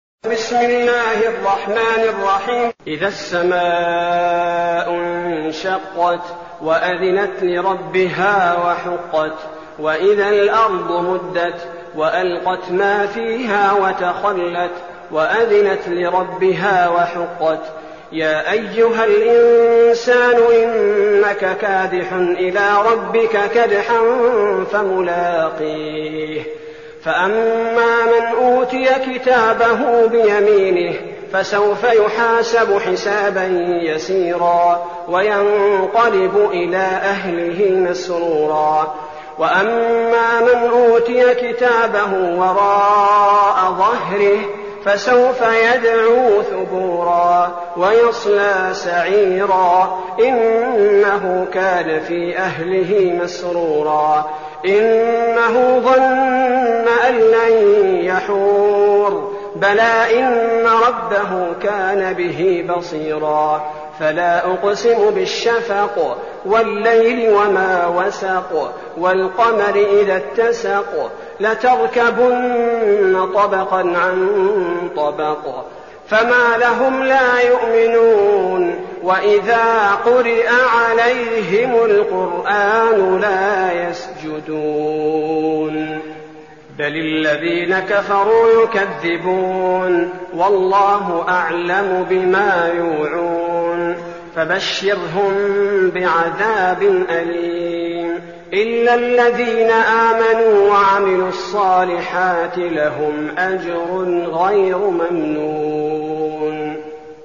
المكان: المسجد النبوي الشيخ: فضيلة الشيخ عبدالباري الثبيتي فضيلة الشيخ عبدالباري الثبيتي الانشقاق